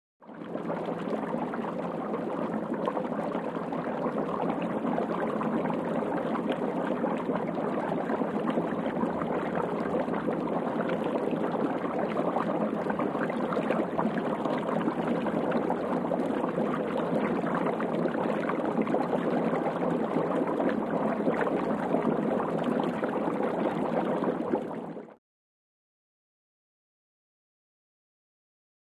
Water Boiling | Sneak On The Lot